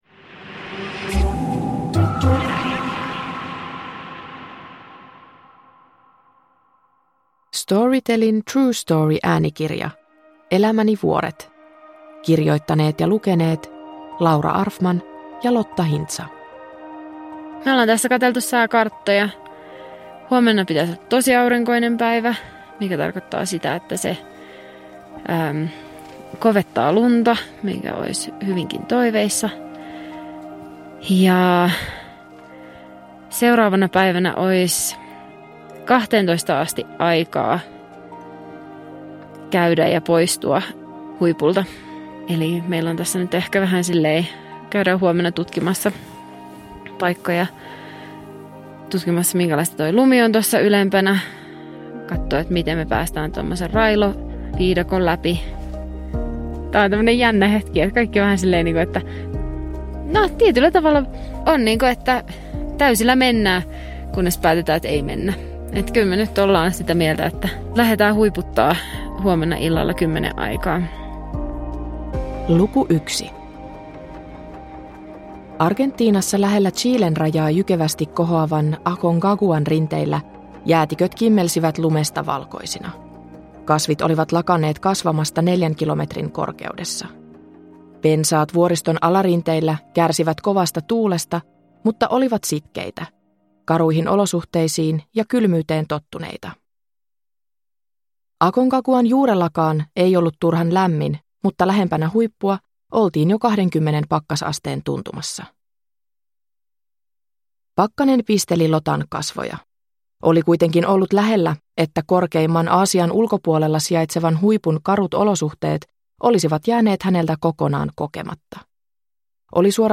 Huiputusyritysten odottamattomia käänteitä ja hengenvaarallisia tilanteita kuvaava äänikirja on tosielämän jännityskertomus.